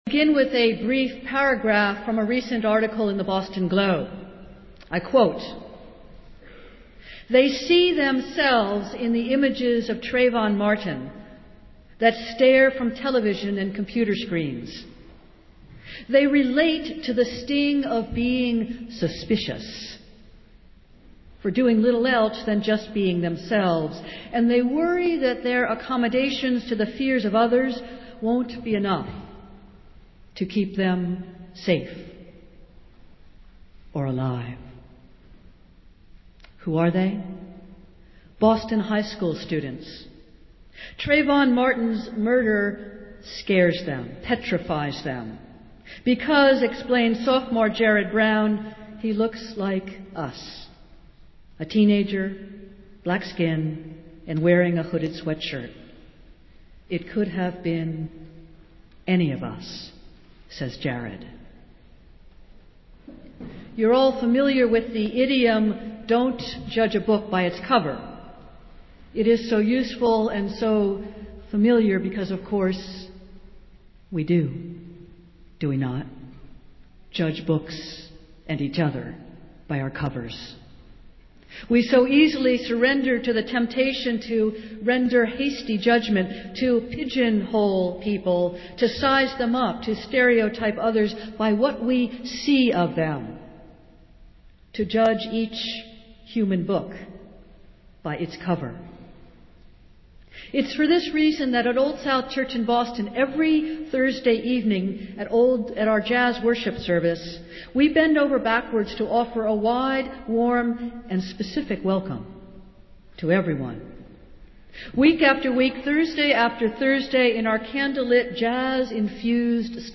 Festival Worship - Marathon Sunday